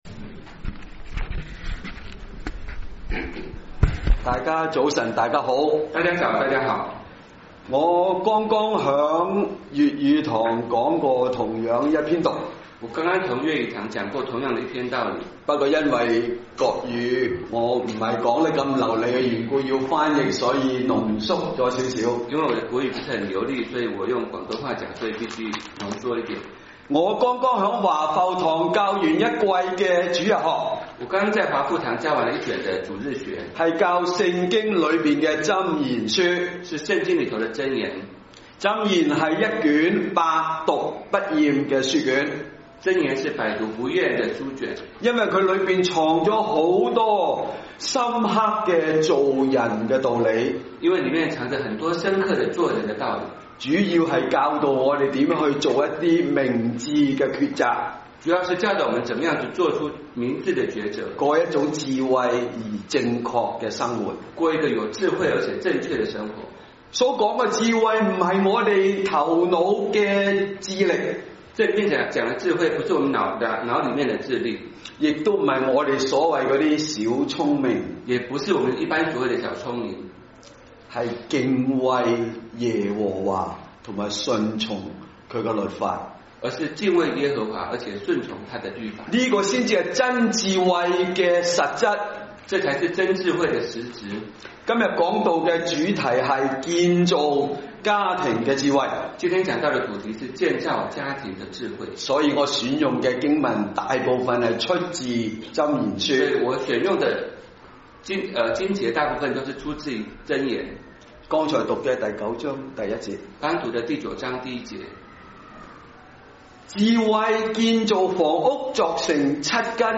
牛頓粵語崇拜 , 講道